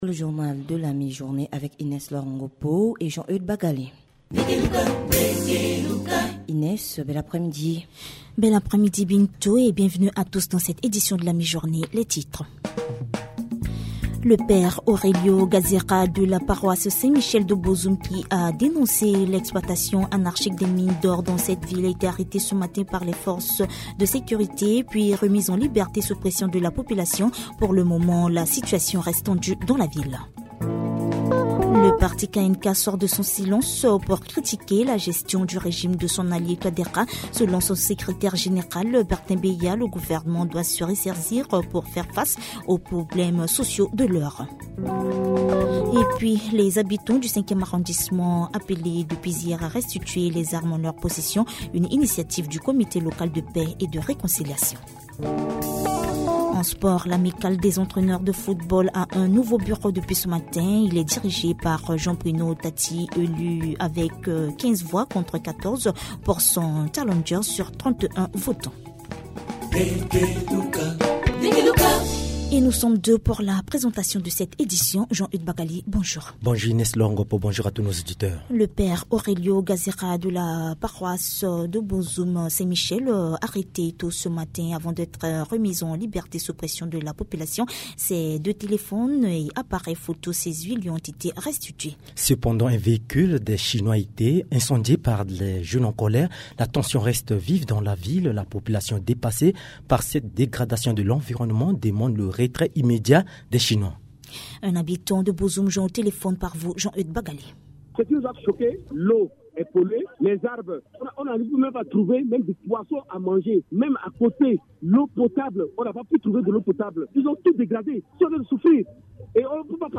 Journal Français